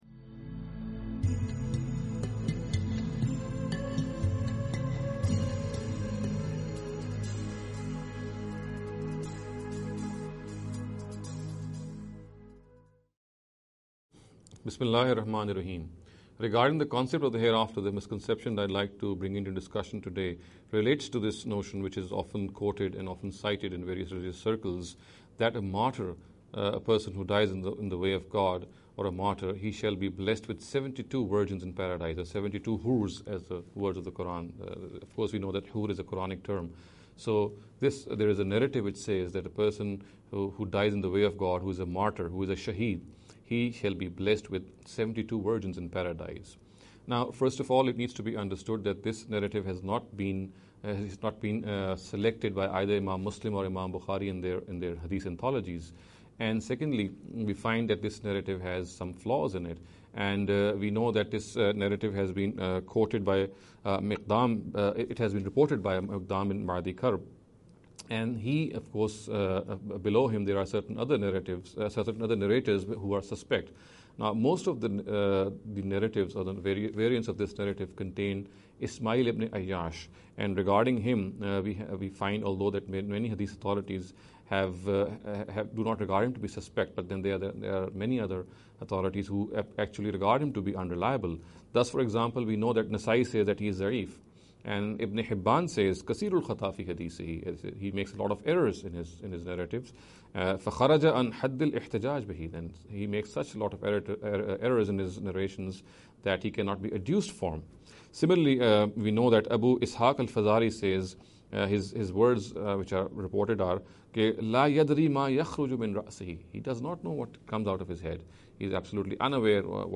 This lecture series will deal with some misconception regarding the Concept of the Hereafter.